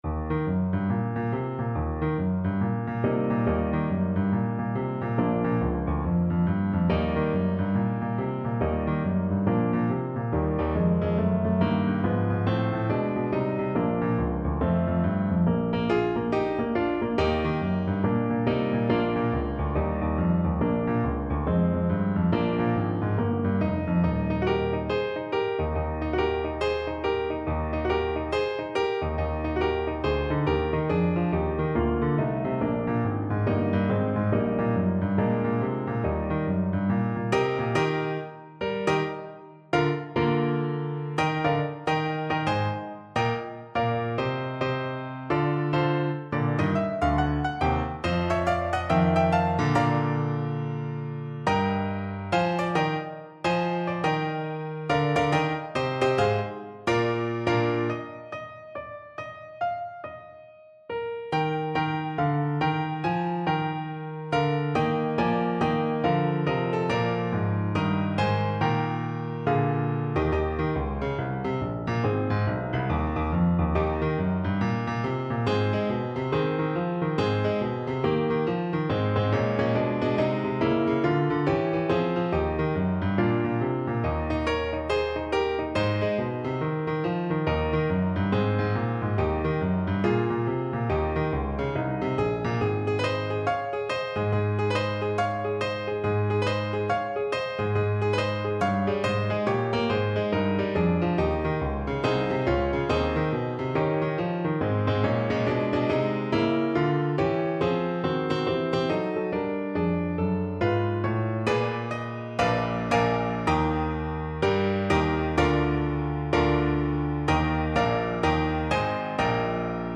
Alto Saxophone
With a swing! =c.140